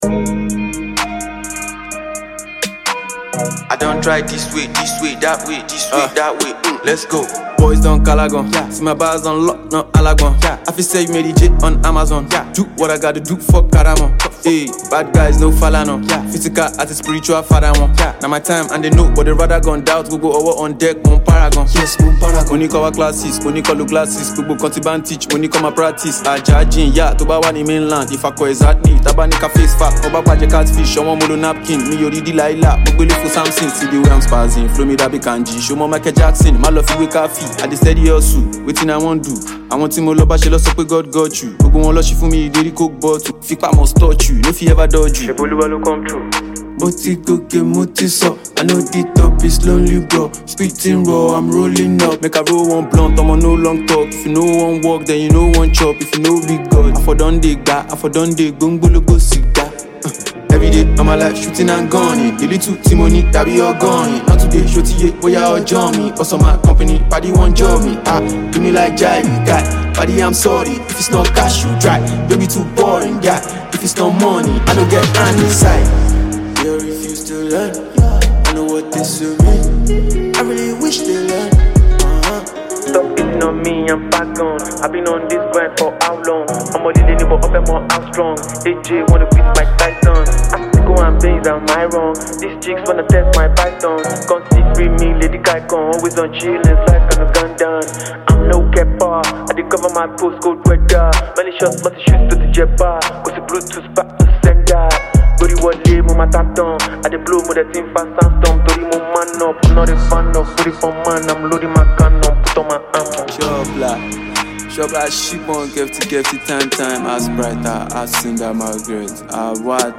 Talented Nigerian rapper